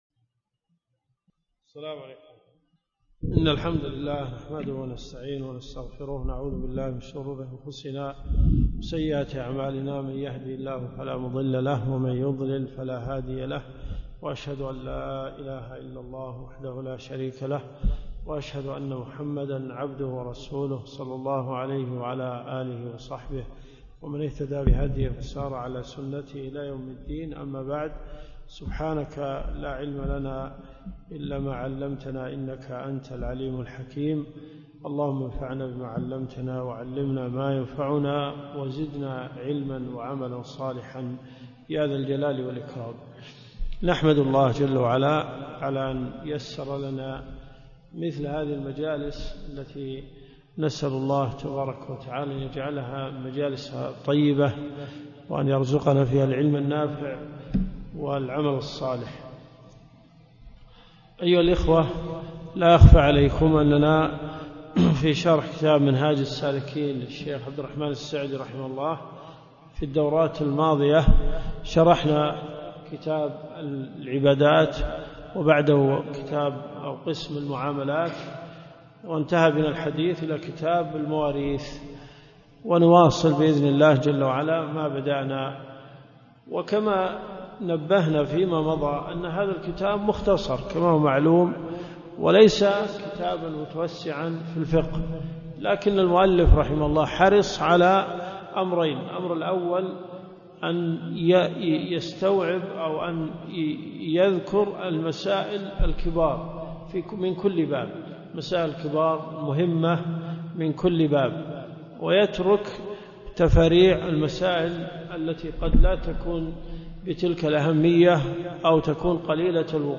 دروس صوتيه
المدينة المنورة . جامع البلوي